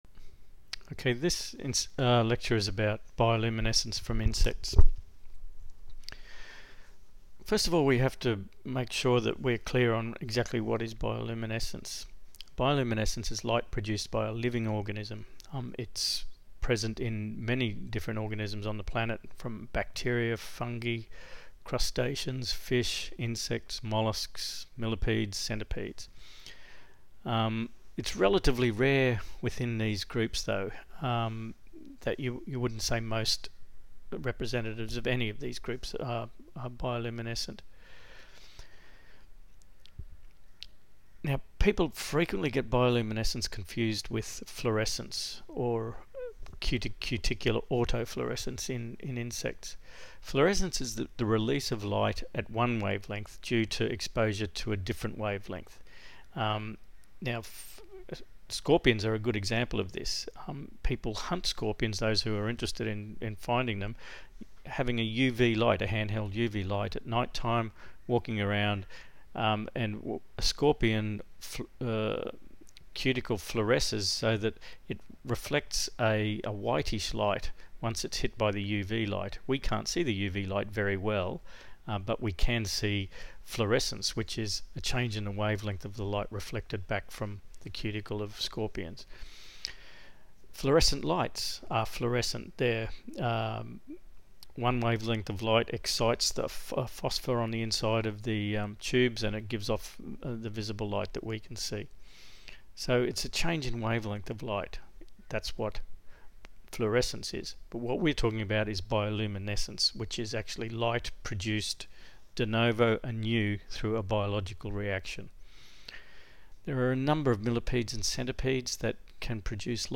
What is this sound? Minilecture: